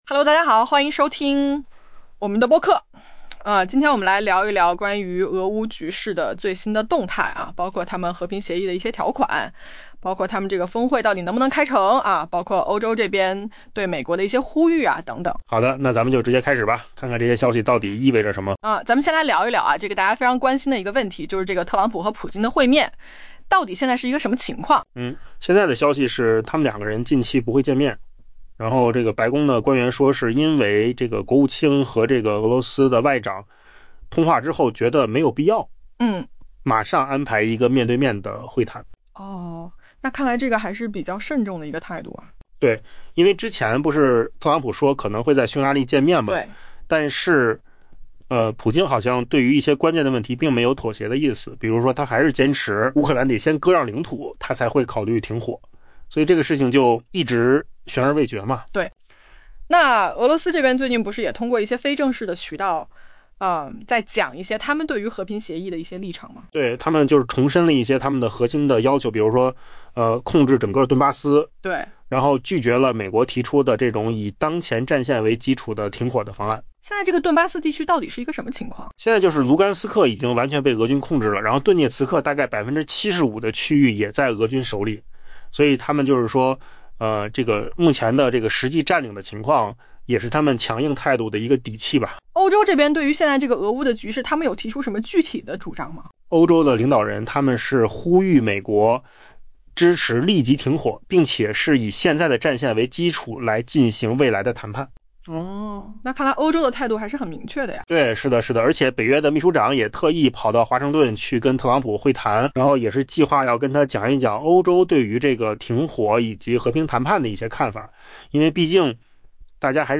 AI 播客：换个方式听新闻 下载 mp3 音频由扣子空间生成 一位白宫高级官员透露，在国务卿鲁比奥与俄罗斯外长拉夫罗夫进行了一次 「富有成效的通话」 后， 双方选择不举行面对面会晤， 「特朗普总统在近期内没有与普京总统会面的计划」。